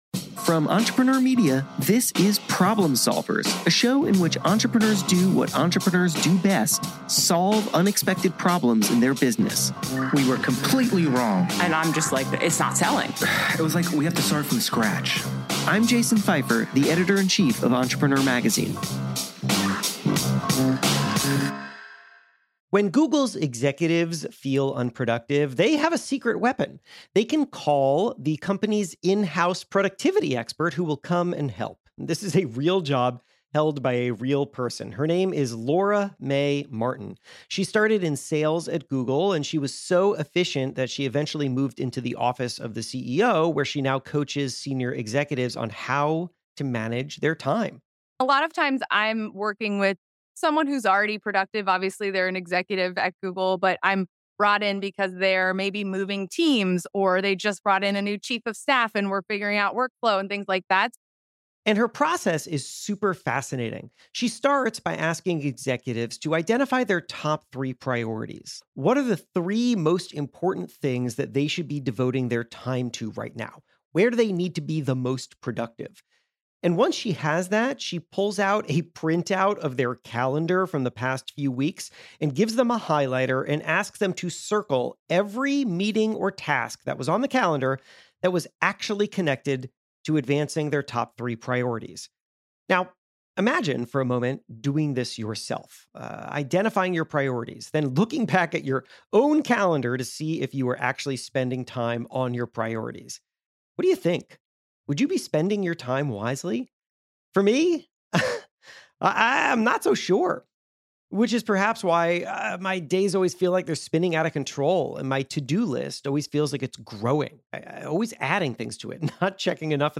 In this conversation, she lays out a plan for focusing your time and your energy.